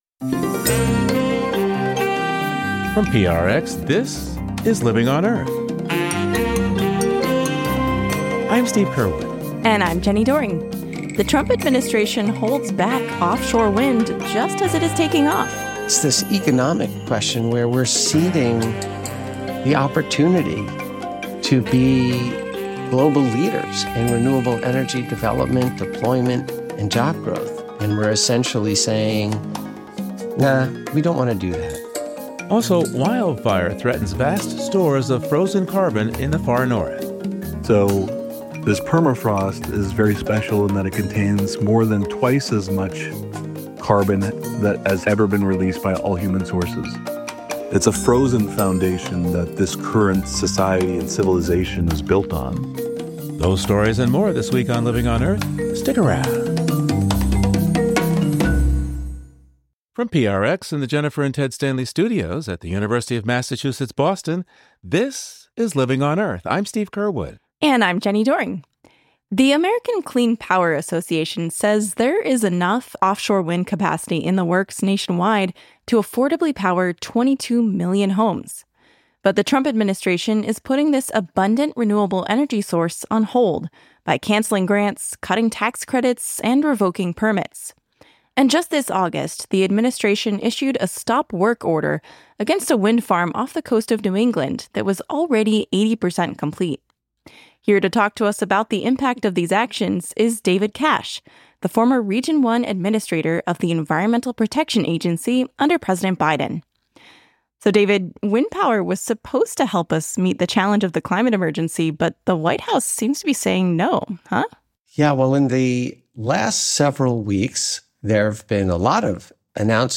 This week's Living on Earth, PRI's environmental news and information program.
BirdNote®: The Auspicious Chime of the Bare-throated Bellbird listen / download The exceptionally loud, metallic call of the Bare-throated Bellbird can be heard almost a mile away.